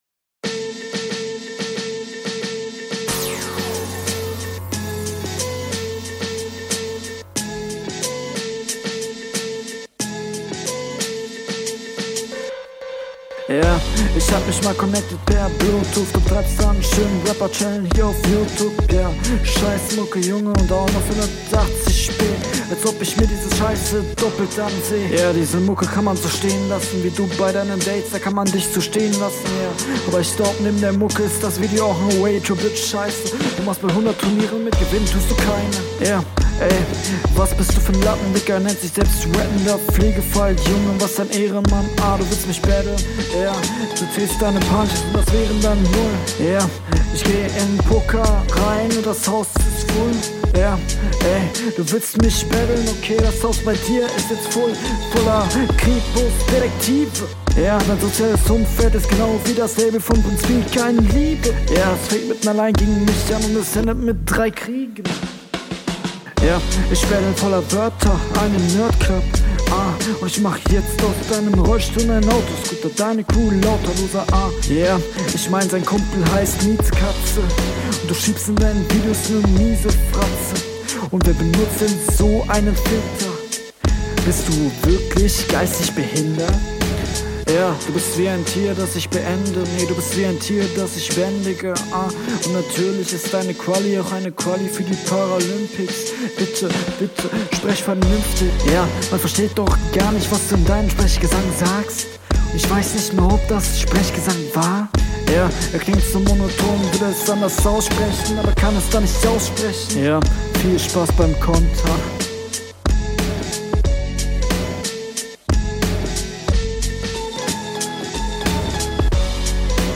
Stimme aus Runde 2 beibehalten auch Abmische ist okay.
Aber cooler Beat!